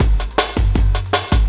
06 LOOP05 -R.wav